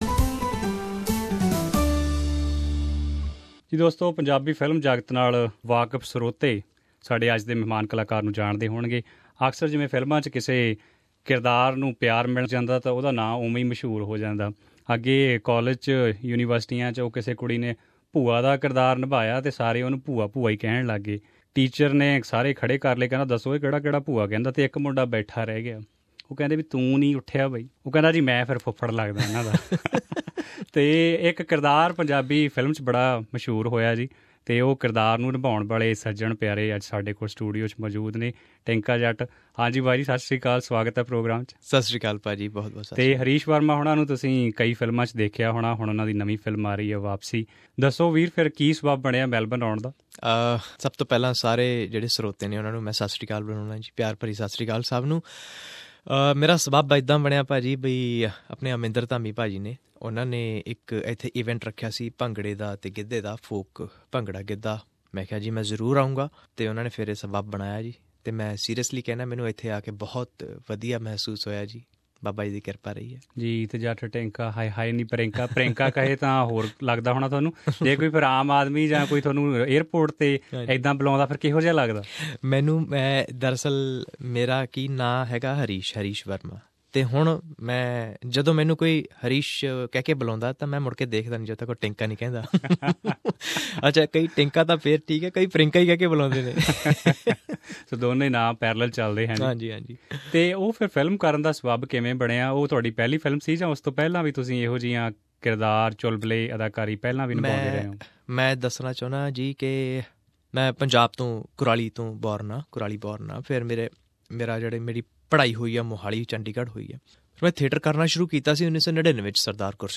He was our studio guest at Melbourne in May 2016.